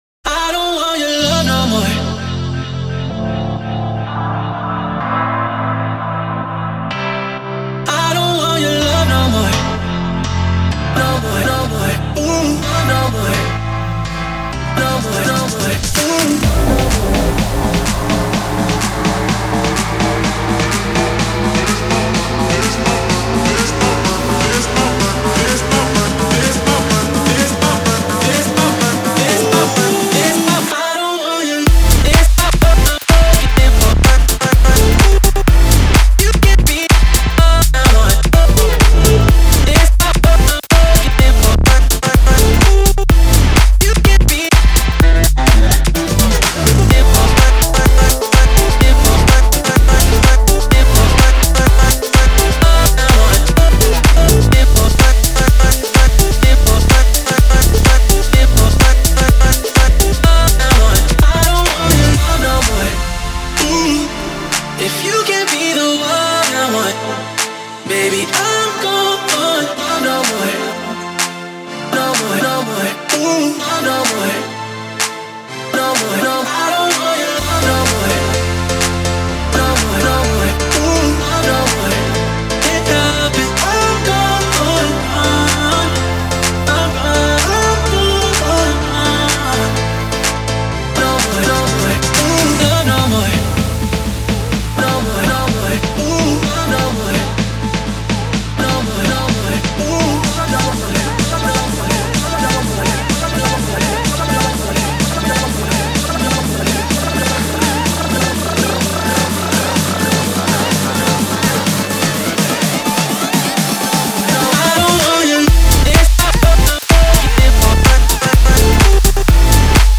Future House